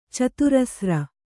♪ caturasra